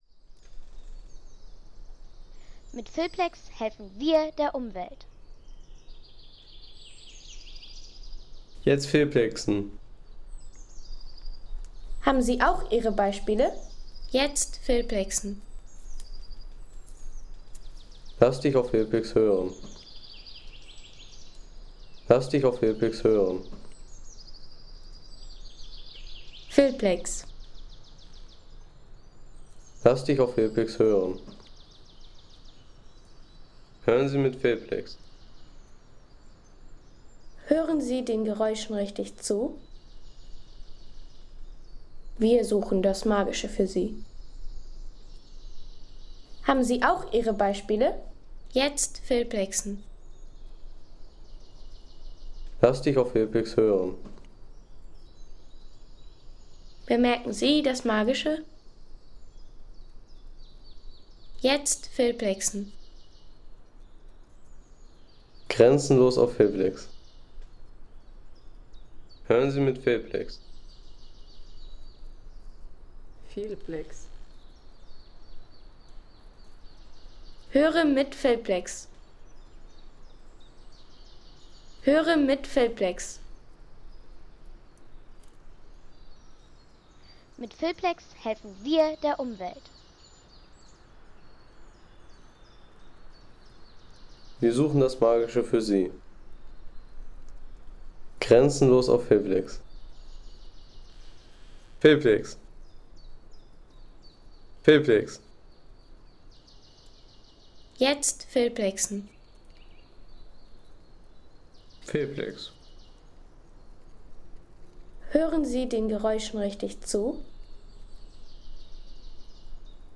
Naturwald Stodthagen
Eintauchen in die Ruhe des Schleswig-Holsteinischen Laubwaldes – Ein ... 3,50 € Inkl. 19% MwSt.